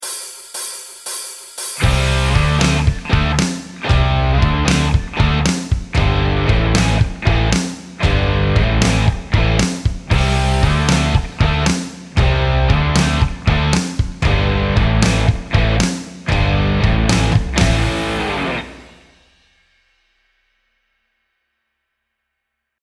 パワーコードをスライドでつなぐギターリフです。